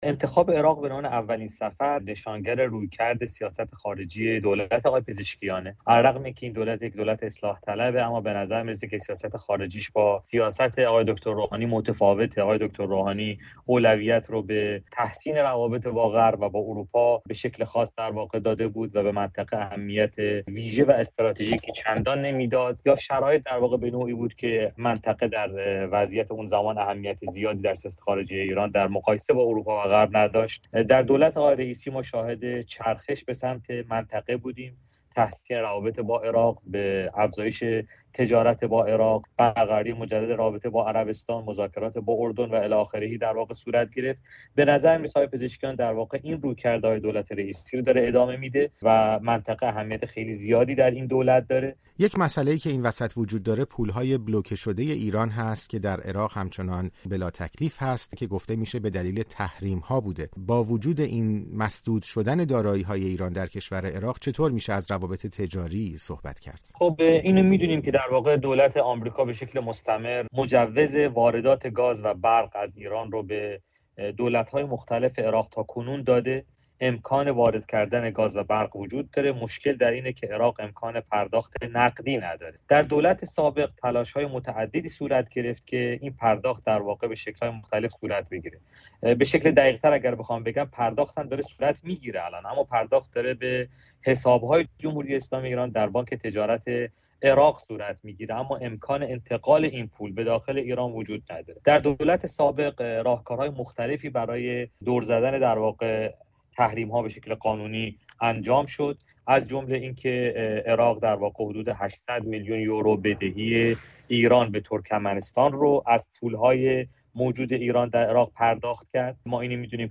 گفتگو با مشاور پیشین نخست‌وزیر عراق درباره سفر پزشکیان به بغداد